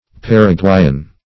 Paraguayan \Par`a*guay"an\, a.